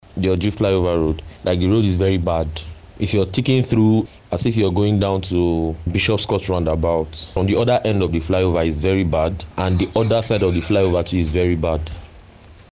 A commuter